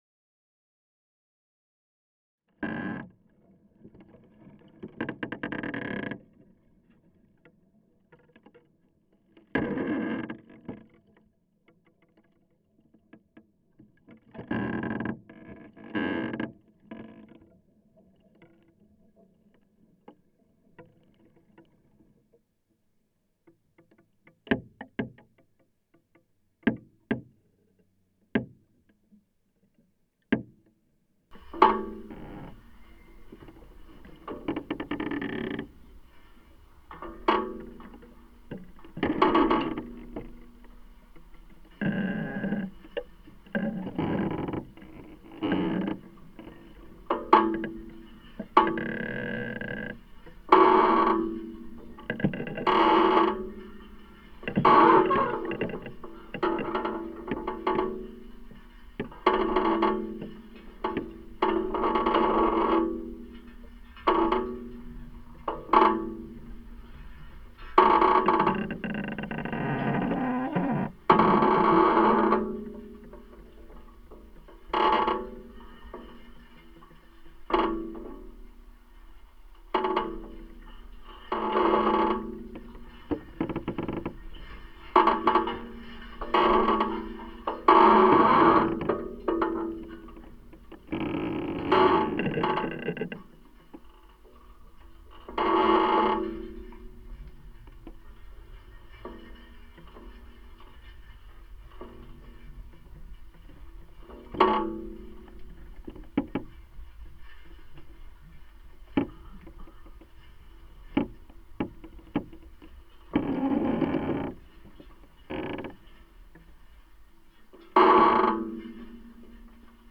sound descriptors: a list of sounds as they appear on the summer walk
Dawn chorus, summer solstice, slow footfall on gravel path, a grasshopper warbler, a barn door opening, a barn door closing, overflowing gutter, a choir of hammers, two buzzards circling, cutting reeds, rain falling on barbed wire, wet leaves, rainfall on the surface of the river Itchen, the mini-stream, a coot on the pond, pondweed photosynthesis, a water boatman, a forest of creaking trees, a cluster of grasshoppers, a bee’s proboscis, wasps chewing on a wooden fence, petrol engine mower, path strimming, ice cream van, children’s voices, cows chewing, Wednesday evening bell practice at Winchester Cathedral, river hatch, a wire in the mini-stream, an evening cloud of Pipistrelles.
The project began in the winter of 2010, with the aim of creating a soundwalk based on recordings made in the Winnall Moors conservation reserve over the period of one year.
Field Recording Series by Gruenrekorder
summer_trees.mp3